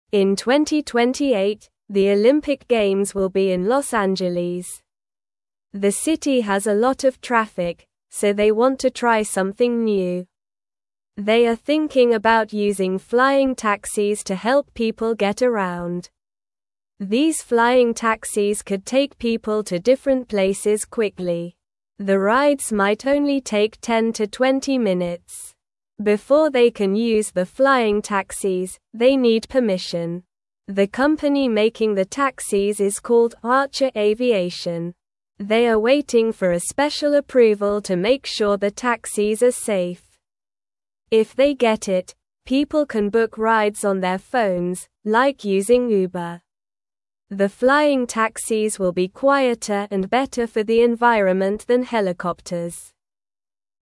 Slow
English-Newsroom-Beginner-SLOW-Reading-Flying-Taxis-Could-Help-at-the-2028-Olympics.mp3